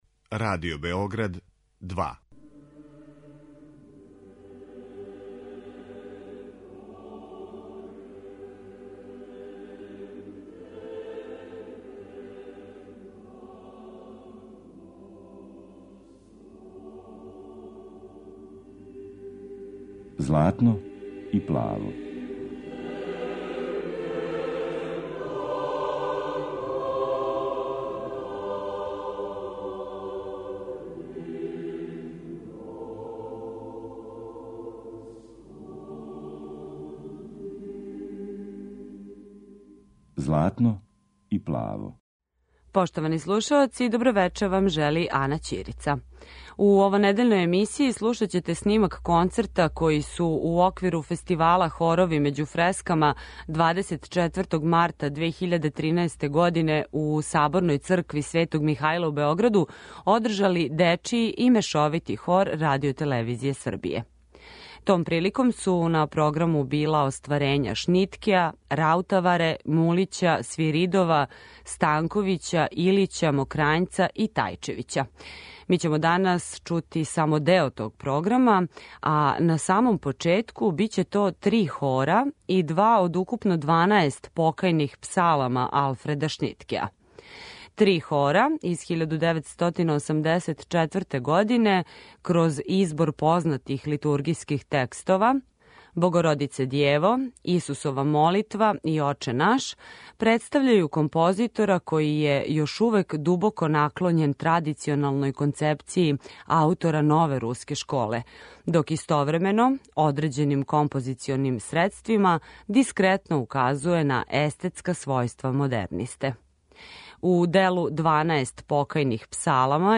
Хорови међу фрескама
Емисија посвећена православној духовној музици.